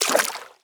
Footstep_Water_05.wav